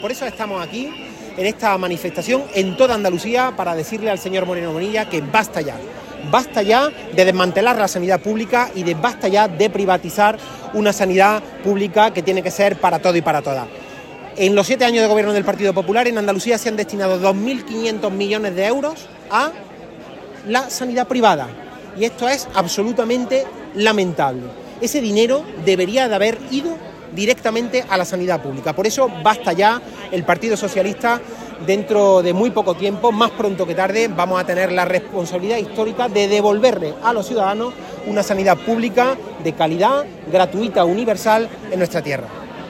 Manifestación de las Mareas Blancas contra la privatización de la sanidad
Cortes de sonido